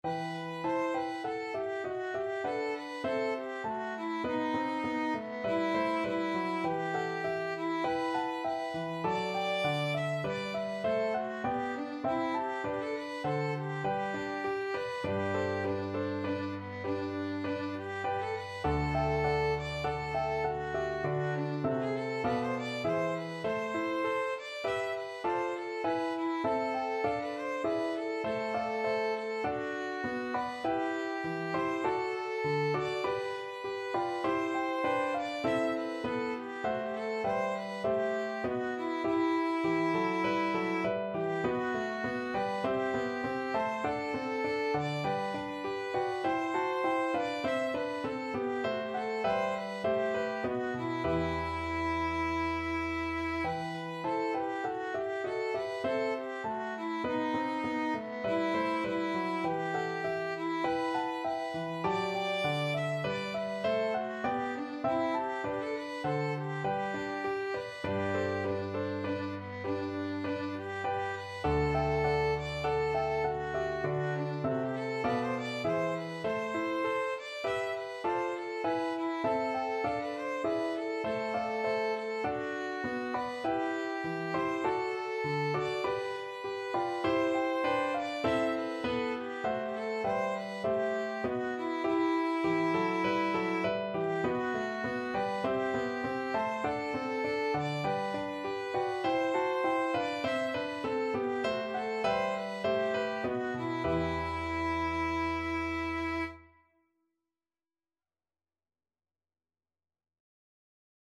Classical Purcell, Henry If Music be the Food of Love Violin version
Violin
E minor (Sounding Pitch) (View more E minor Music for Violin )
4/4 (View more 4/4 Music)
if_music_be_VLN.mp3